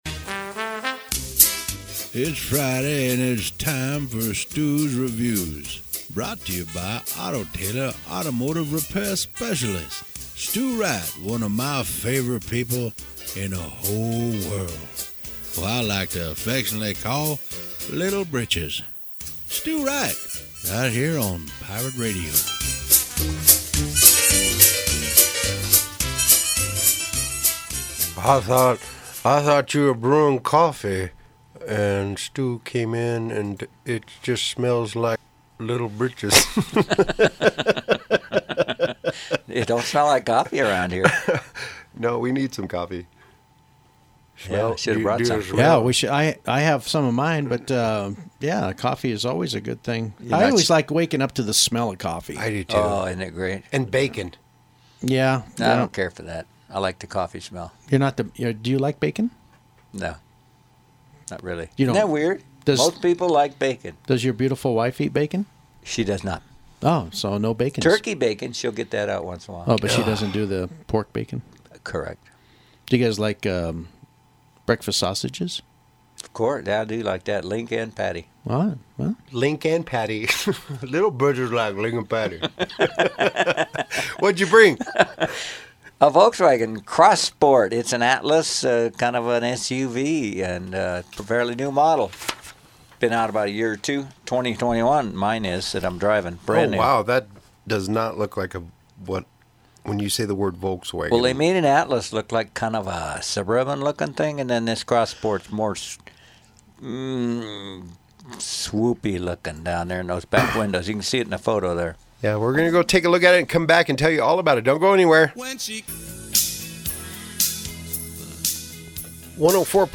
The Atlas was driven to Pirate Radio for a review